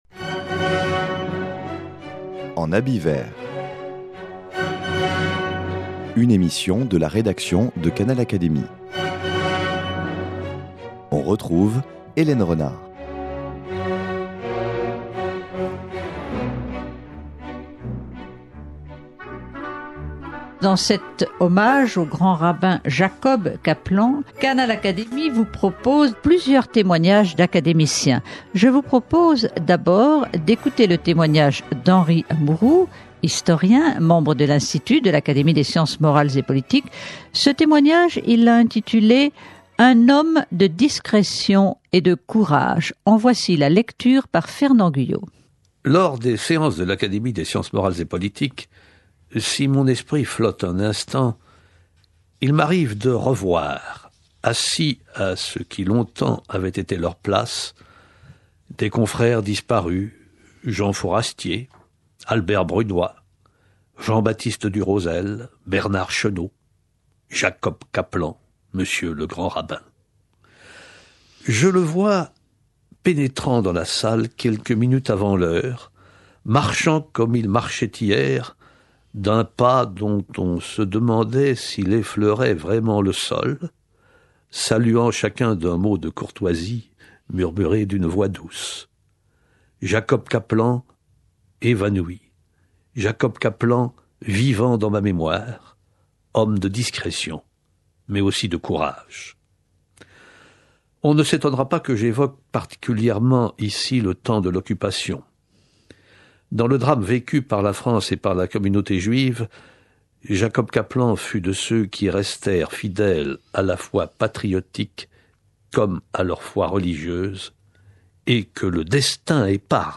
Un timbre et une médaille d’art à son effigie ont été édités. A l’occasion de la parution du timbre, en 2005, plusieurs discours lui ont rendu hommage ainsi qu’un message du Président de la République.